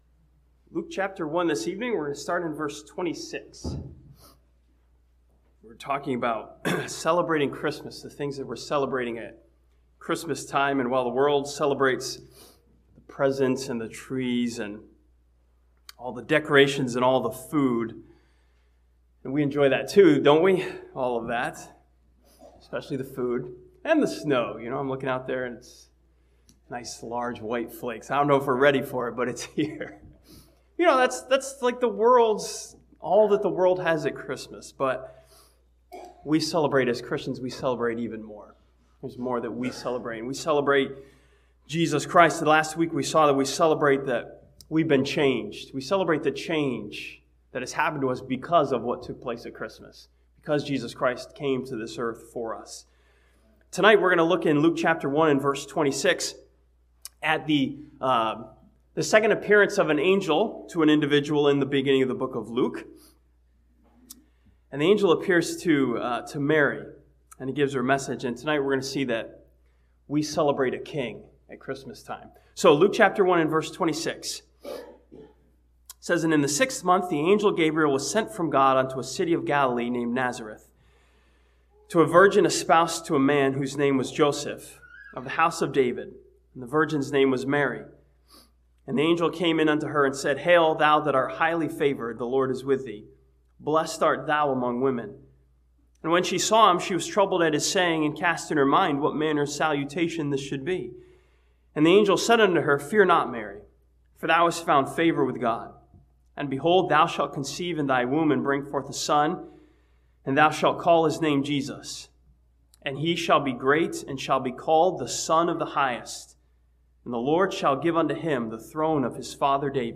This sermon from Luke chapter 1 sees Christmas as a time for Christians to celebrate Jesus Christ as our King.